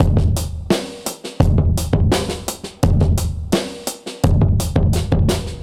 Index of /musicradar/dusty-funk-samples/Beats/85bpm/Alt Sound
DF_BeatA[dustier]_85-03.wav